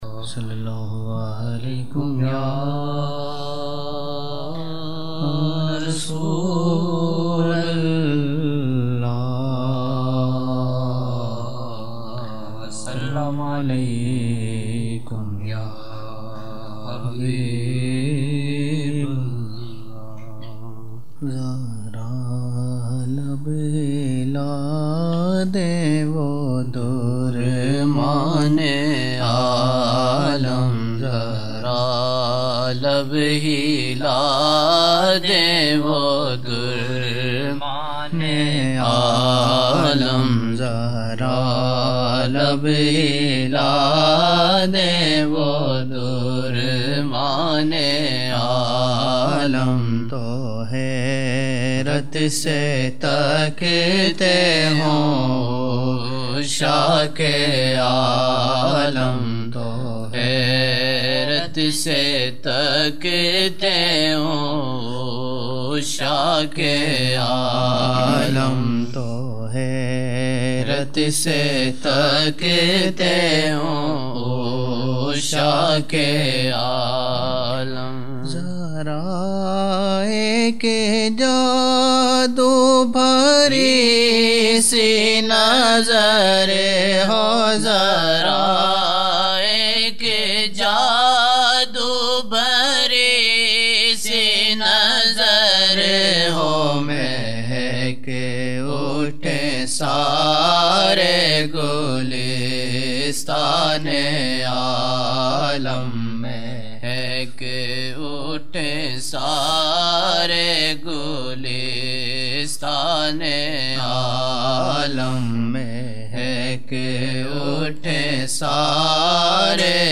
17 November 1999 - Maghrib mehfil (9 Shaban 1420)
Naat shareef: